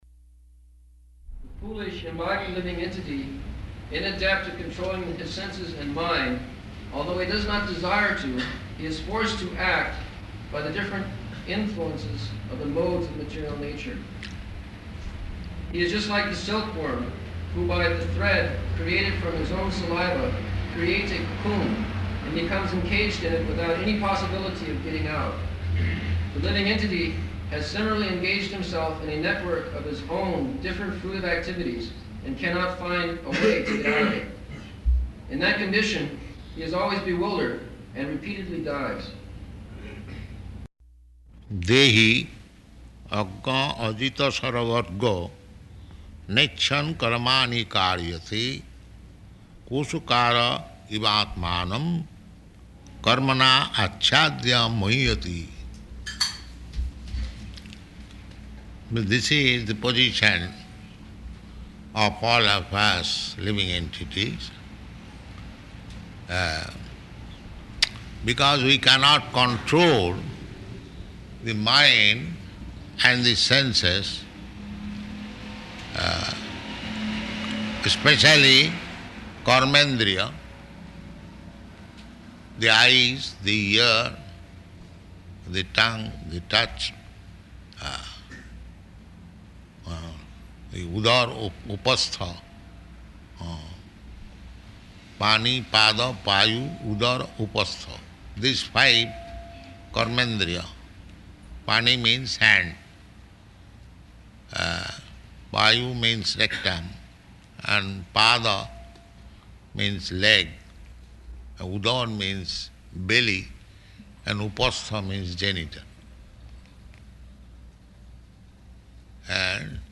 Location: Detroit